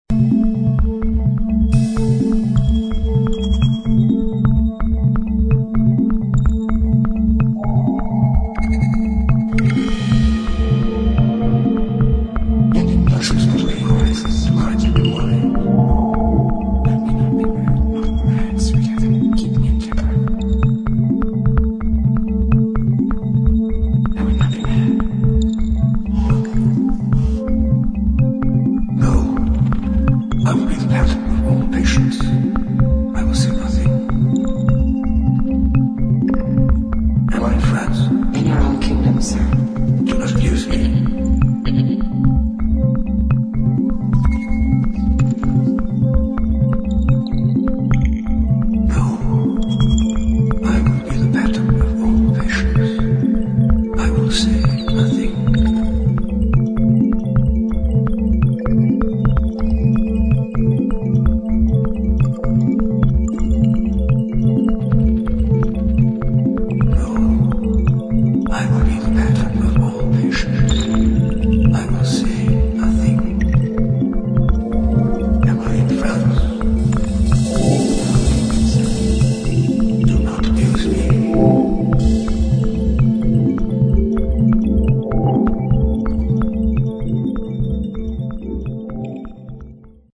[ DEEP HOUSE / EXPERIMENTAL / TECHNO ]